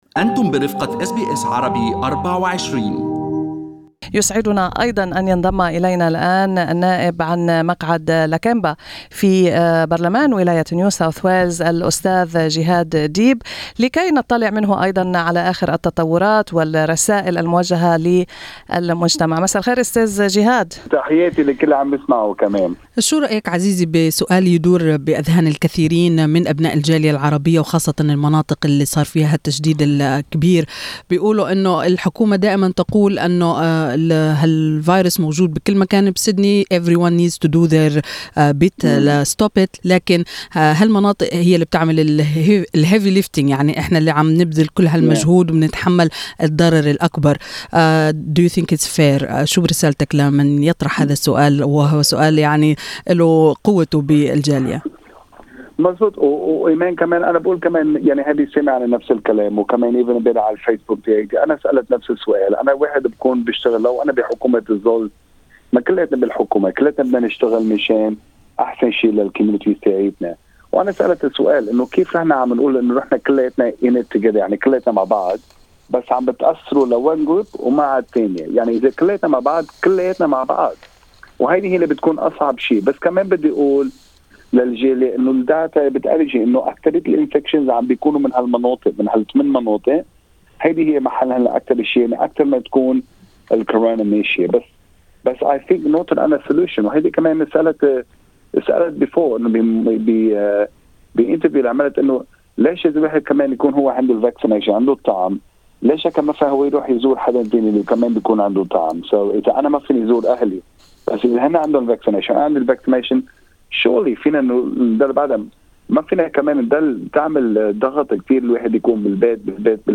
استمعوا إلى اللقاء مع النائب جهاد ديب في المدونة الصوتية في أعلى الصفحة.